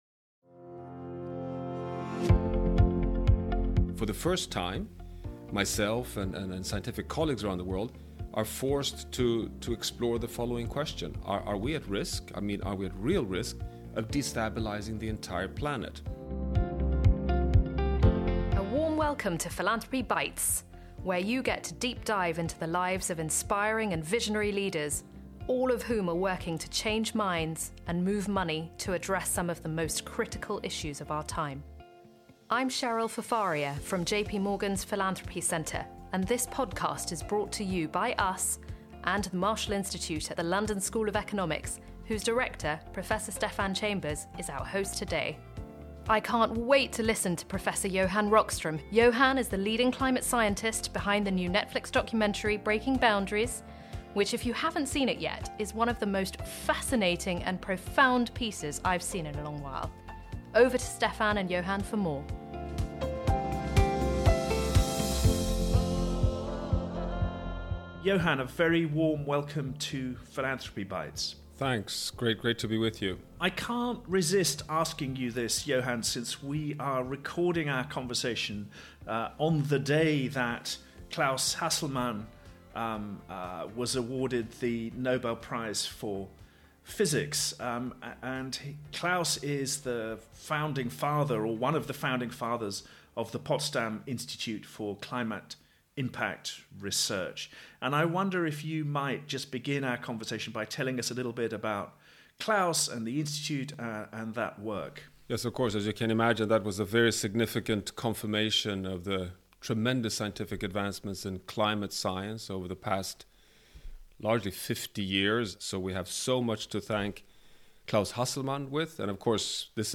Recorded just days before the start of the UN Climate Change Conference of the Parties (COP26) in Glasgow, this discussion explains the implications of 1.5 degree warming, and what needs to be done to avert further crisis.